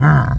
Orc_Hurt.wav